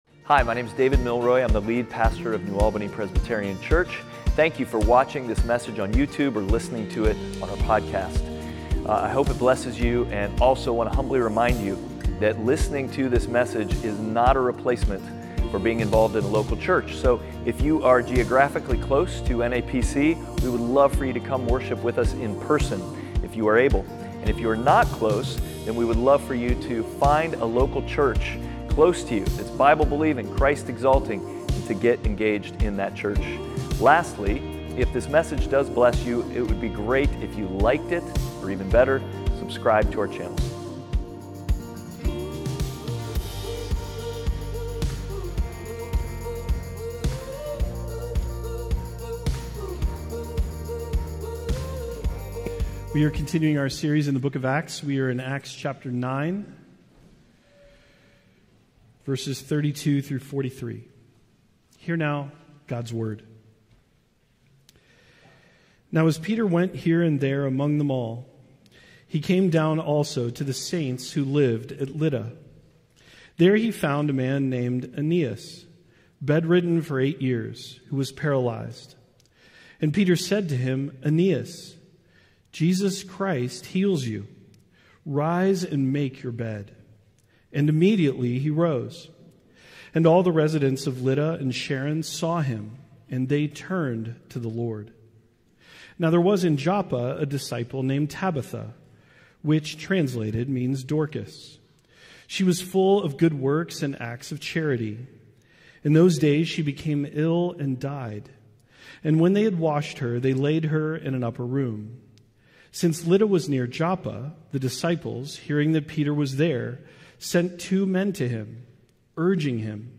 Outward Passage: Acts 9:32-43 Service Type: Sunday Worship « Outward